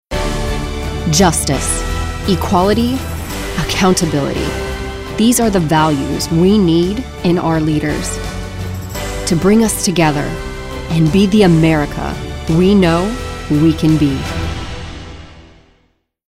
announcer, authoritative, confident, political, young adult